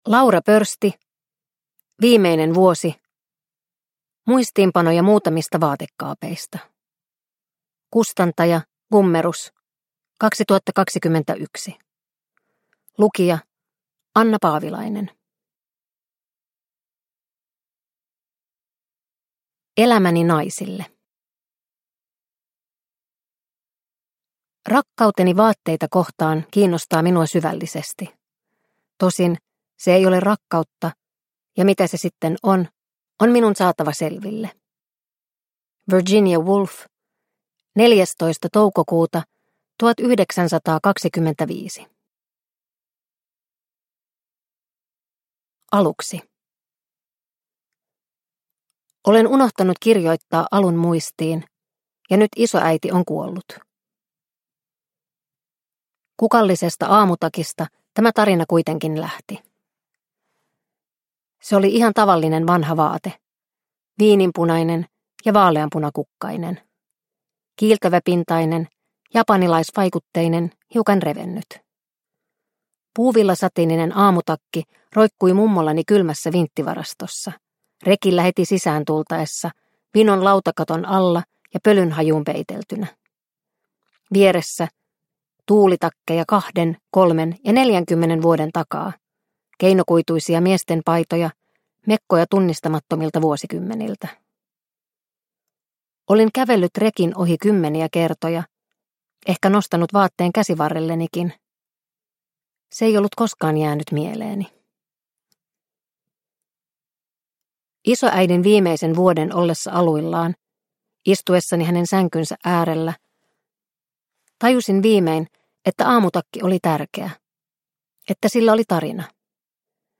Viimeinen vuosi – Ljudbok – Laddas ner